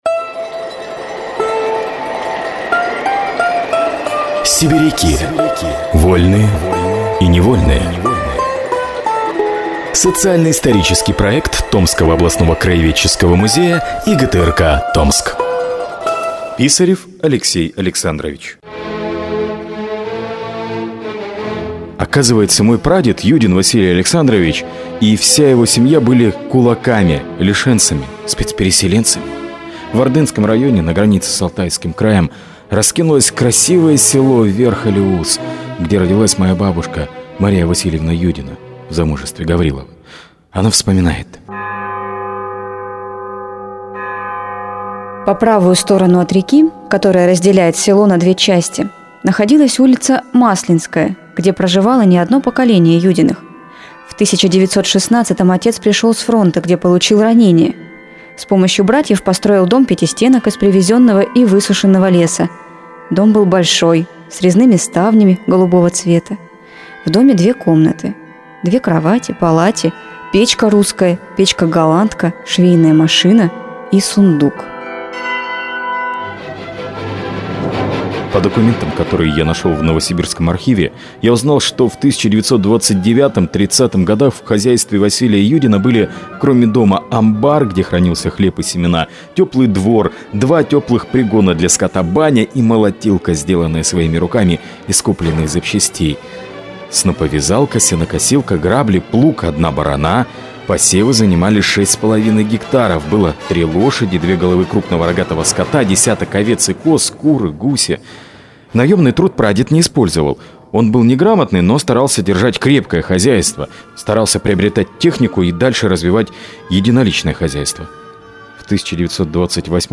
Мужской голос
Женский